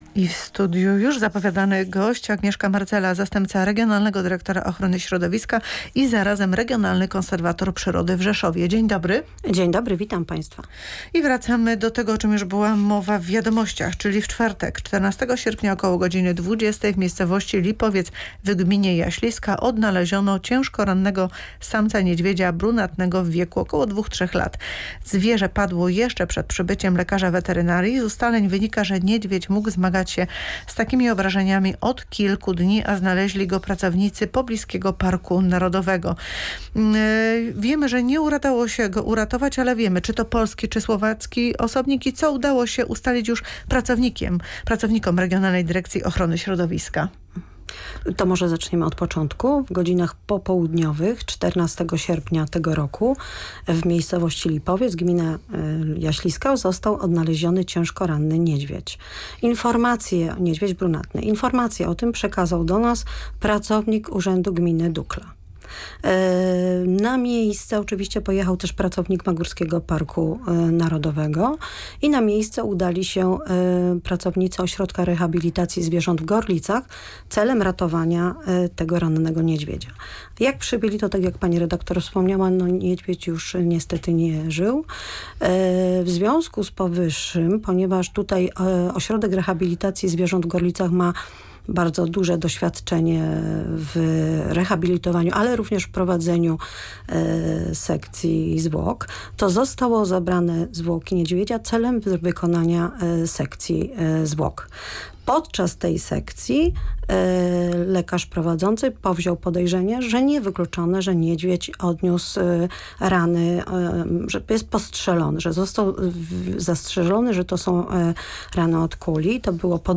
Więcej w rozmowie